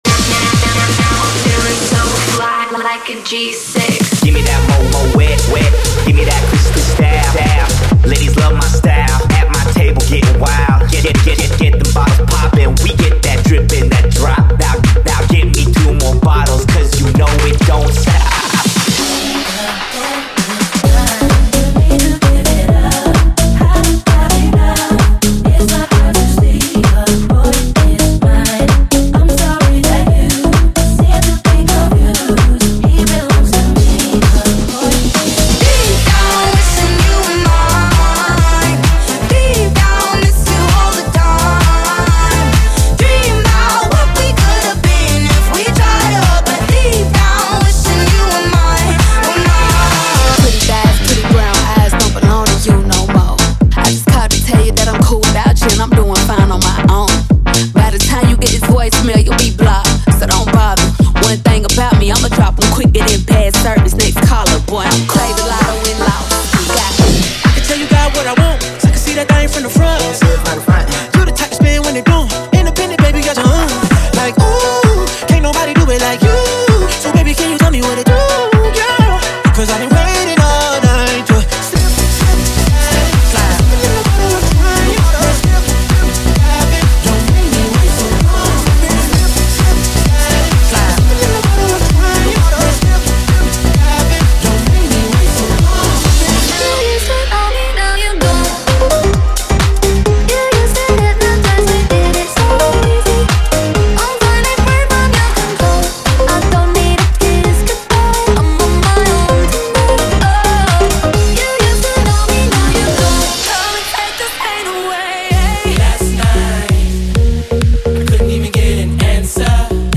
BPM: 130|140|150 (58:00)
Format: 32COUNT
Soulful House, Smooth Anthem & touch of Club.